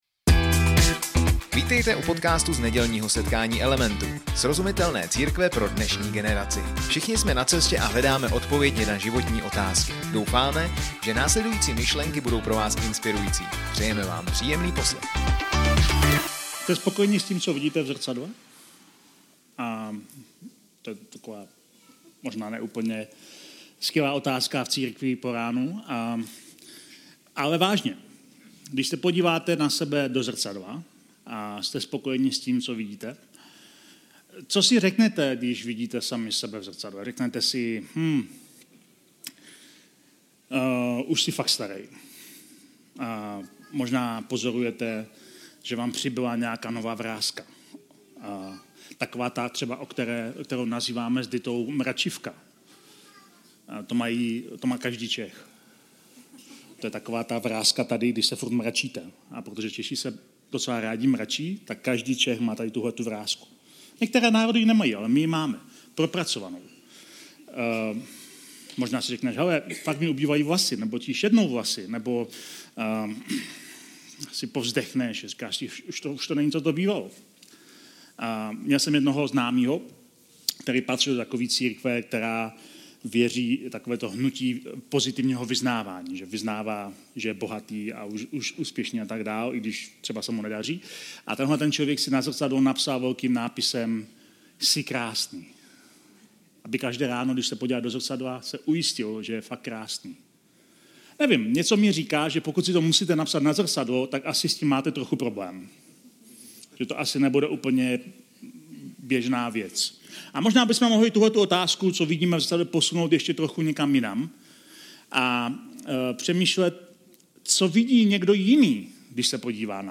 Element - srozumitelná církev, která rozumí lidem. Každou neděli přinášíme na svých setkáních relevantní a praktickou přednášku na téma křesťanské víry, většinou tematicky spojenou v sérii několika dalších přednášek.